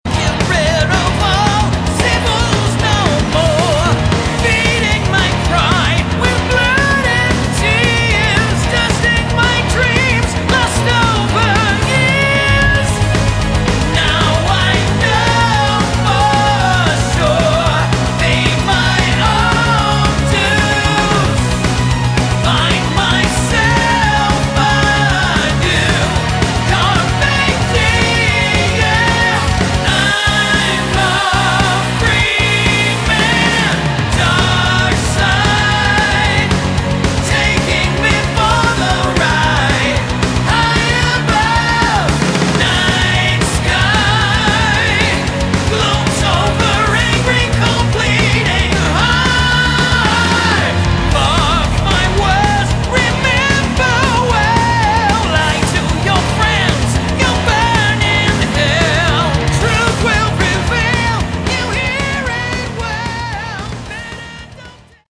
Category: Melodic Metal
lead and backing vocals
guitars, bass, keyboards
drums